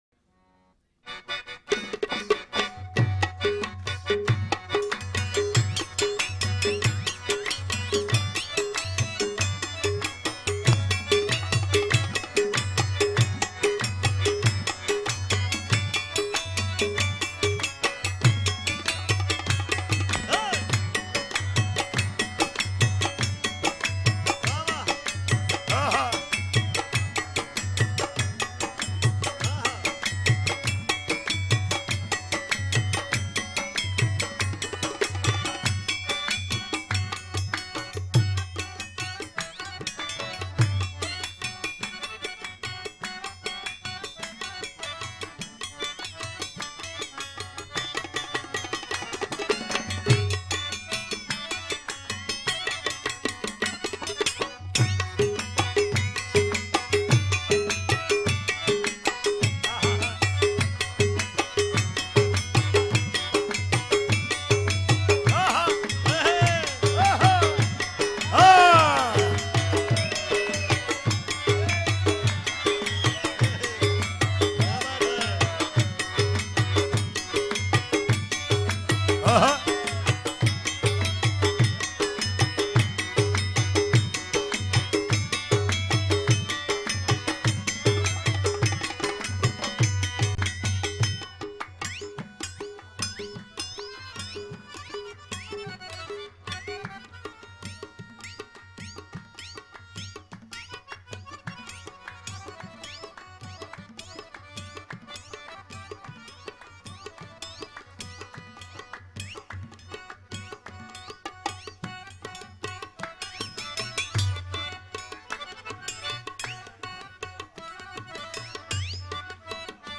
Qawwali Songs